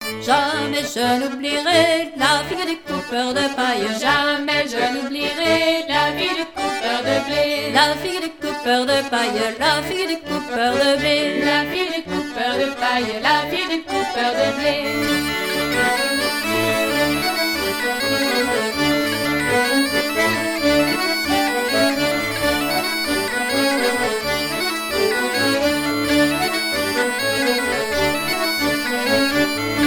Couplets à danser
danse : branle ;
Pièce musicale éditée